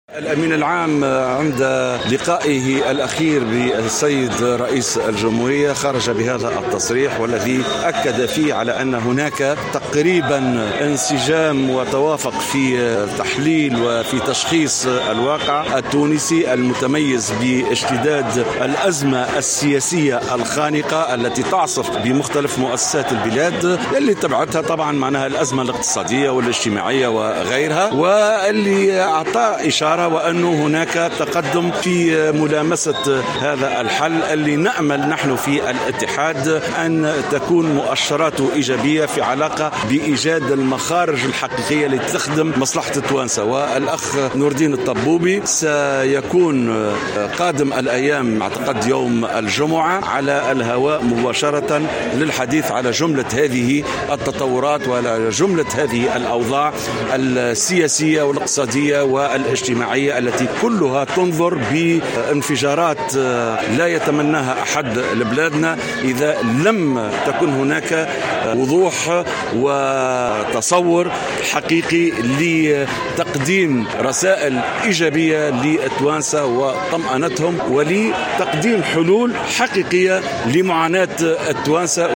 قال الامين العام المساعد للاتحاد العام التونسي للشغل سمير الشفّي اليوم في تصريح لـ "الجوهرة اف ام" إن الأمين العام للمنظمة الشغيلة، نور الدين الطبوبي، كان أكد ان هناك بوادر انفراج للأزمة السياسية شرط تغليب المصلحة الوطنية على المصالح الحزبية.